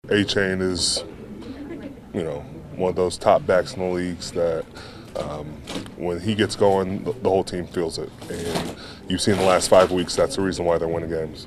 Heyward says Achane is a difference maker.